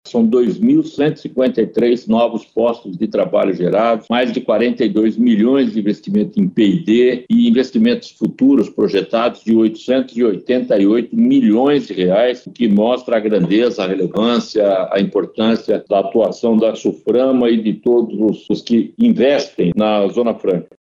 A reunião de número 320 do Conselho de Administração da Suframa – CAS ocorreu na manhã desta quarta-feira 27/08 por videoconferência.
O secretário-executivo do Ministério do Desenvolvimento, Indústria, Comércio e Serviços – MDIC, Márcio Fernando Rosa, que presidiu a sessão, destacou os resultados positivos do encontro.
SONORA-1-REUNIAO-CAS-.mp3